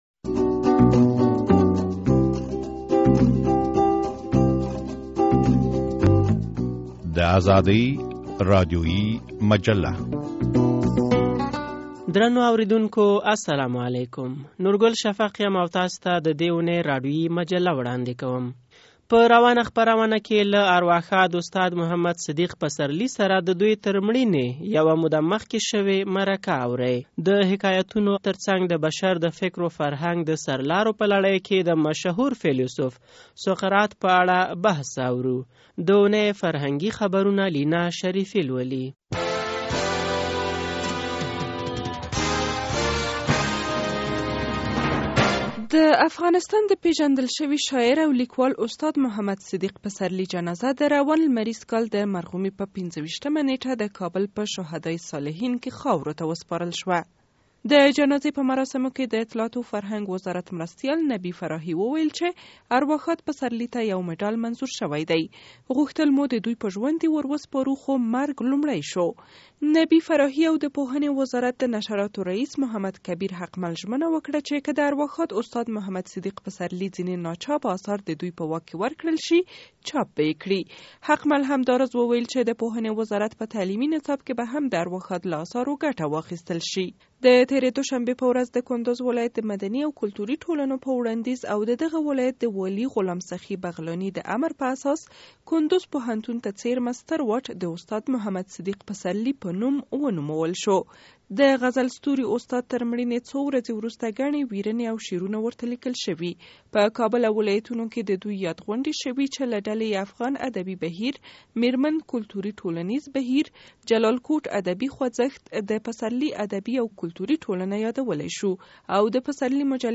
له ارواښاد استاد محمدصديق پسرلي سره شوې مرکه